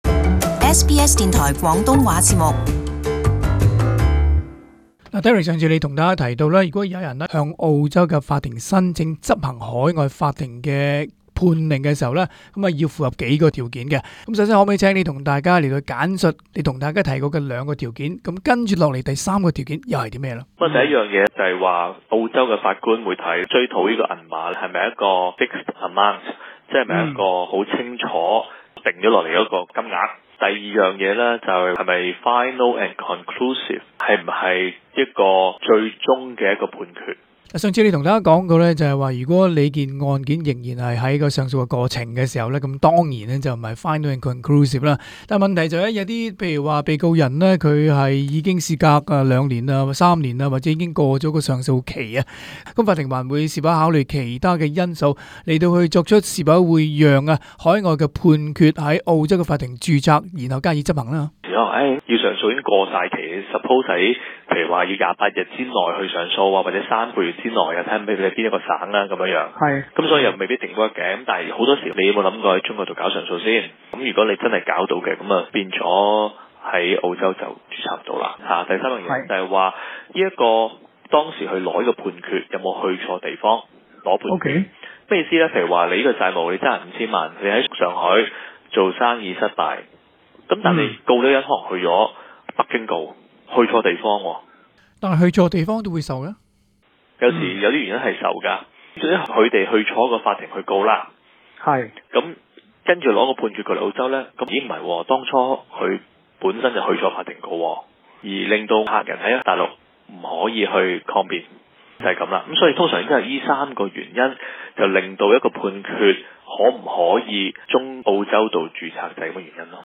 AAP SBS广东话播客 View Podcast Series Follow and Subscribe Apple Podcasts YouTube Spotify Download